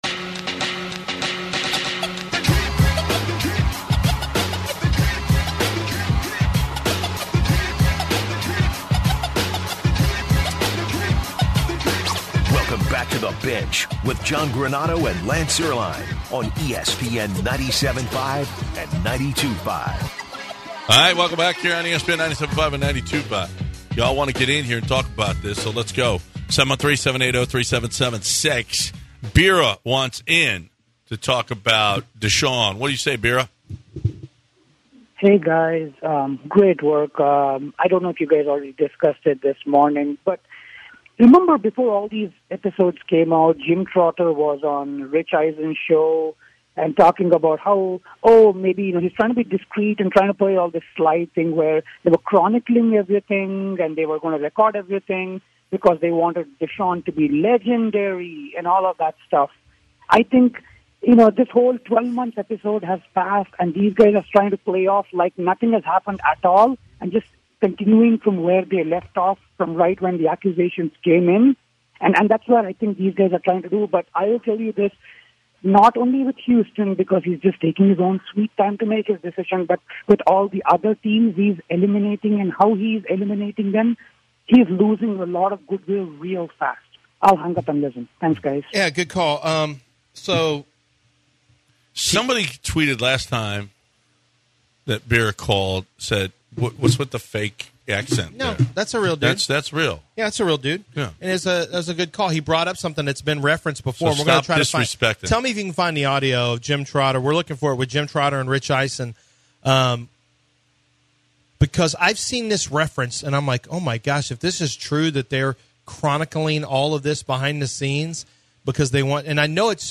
In the second hour the guys take some calls from listeners and then talk about the Atlanta Falcons and Matt Ryan. At the bottom of the hour the guys talk some Rockets basketball.